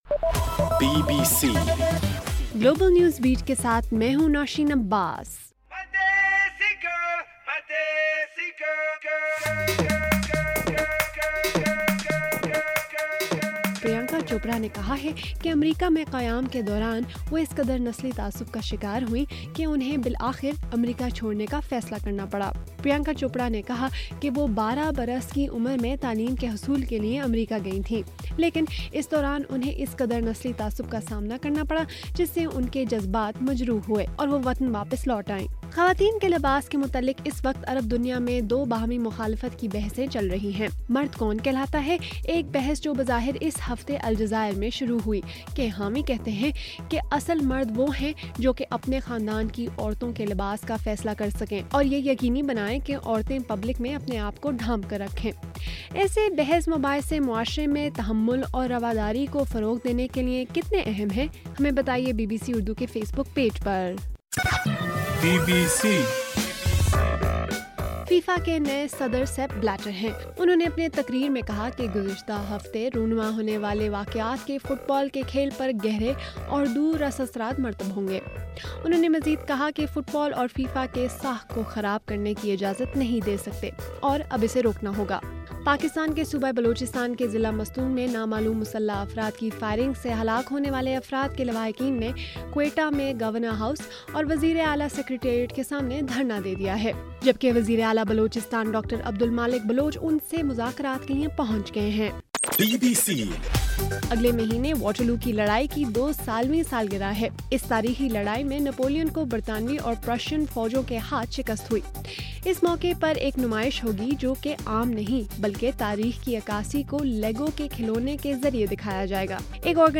مئی 30: رات 11 بجے کا گلوبل نیوز بیٹ بُلیٹن